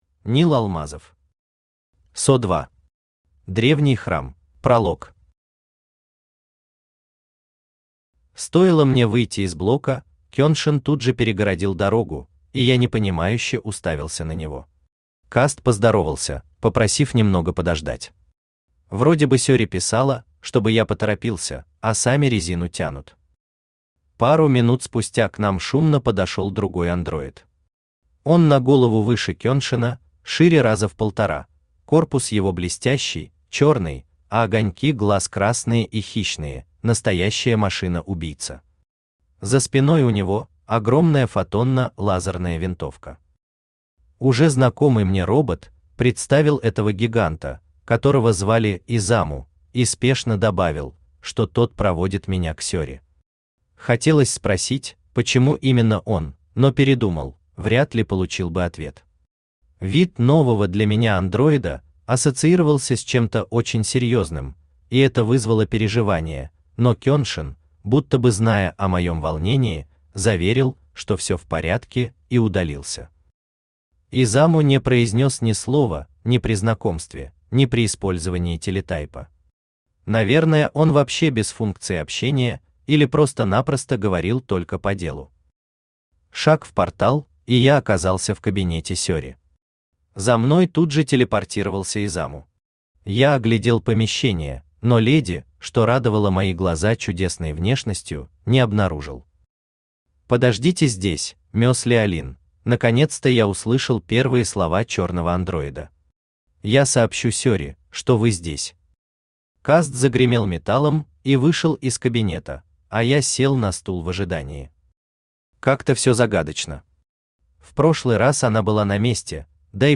Аудиокнига PSO 2. Древний Храм | Библиотека аудиокниг
Древний Храм Автор Нил Алмазов Читает аудиокнигу Авточтец ЛитРес.